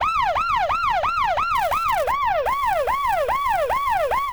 Siren FX with Doppler Effect
siren_doppler.wav